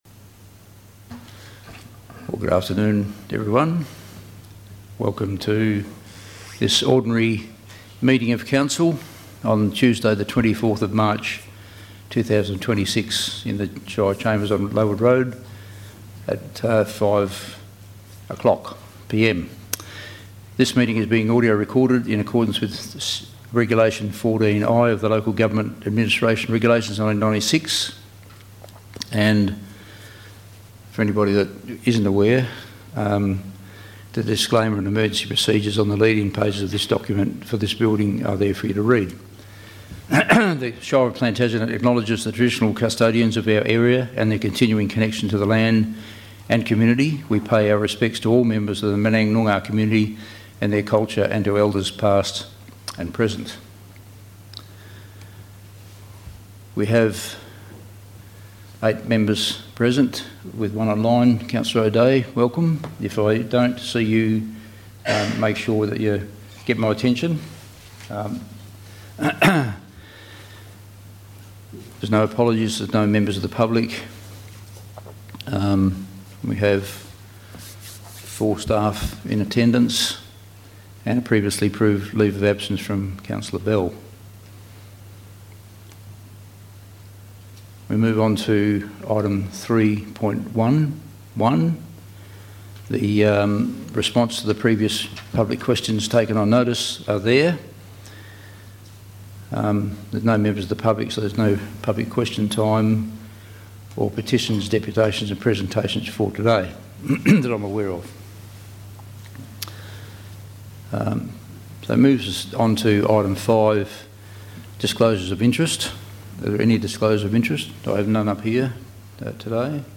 Ordinary Council Meeting - Tuesday 24 March 2026 - 5:00pm » Shire of Plantagenet